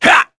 Crow-Vox_Attack1.wav